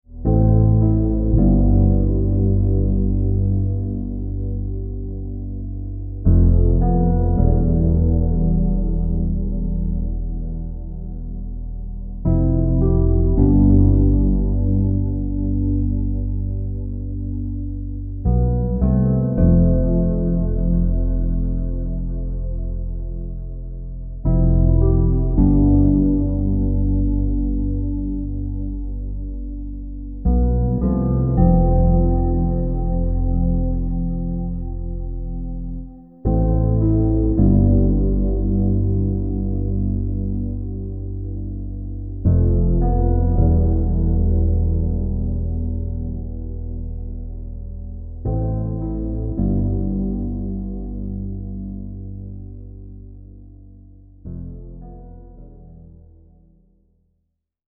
Un viaggio multisensoriale fatto di suono musica e parole in tempo reale.
SUONO – ARMONIA – PERCEZIONI
La componente sonora è infatti basata su una contemporanea sinergia musicale e vocale ove la musica, composta da particolari frequenze e armoniche, accompagna e sostiene la componente vocale che narra, guida, suggerisce il paesaggio interiore che il partecipante si trova a visualizzare e vivere.